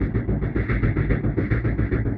Index of /musicradar/rhythmic-inspiration-samples/110bpm